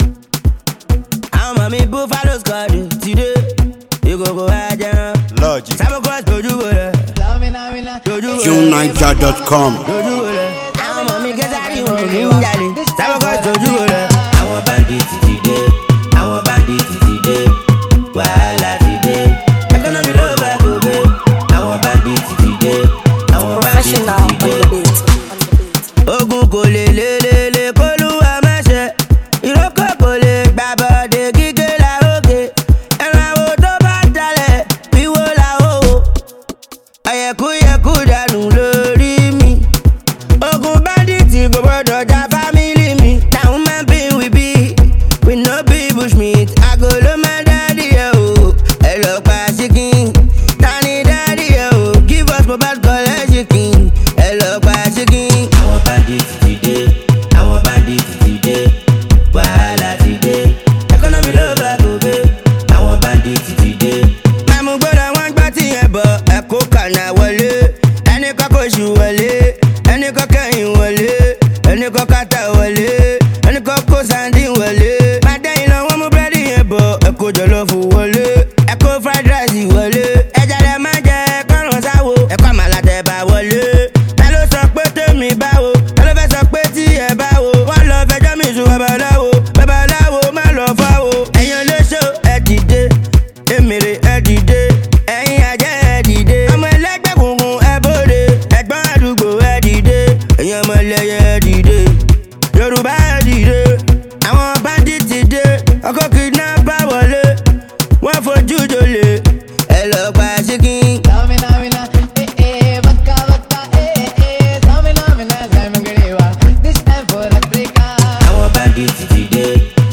a wild, daring, and unabashedly unique street anthem